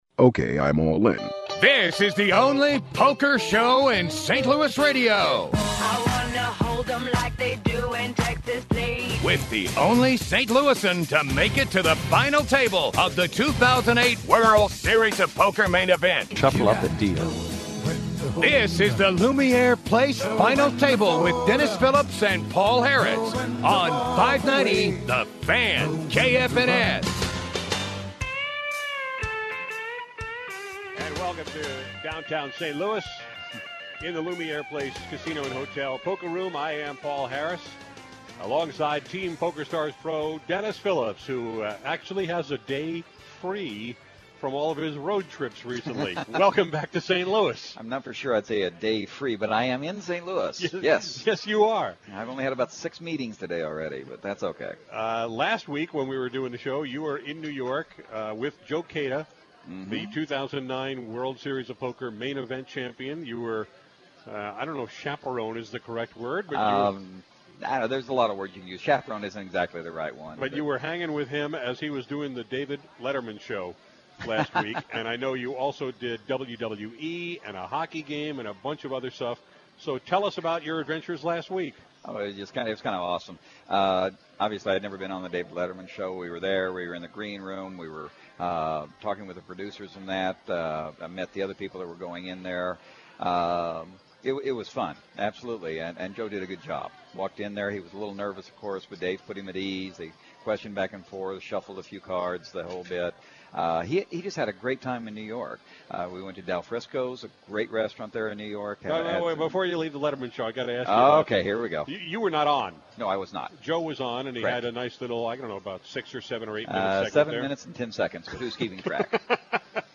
poker radio show